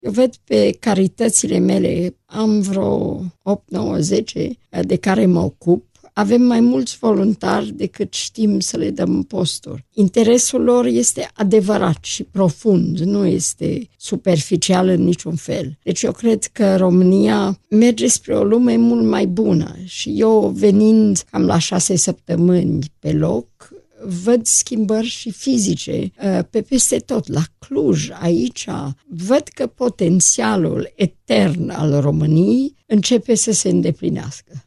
Într-un interviu acordat Europa FM, în urmă cu un an, prințesa Marina Sturdza mărturisea la emisiunea La Radio, că potențialul etern al României începe să se îndeplinească.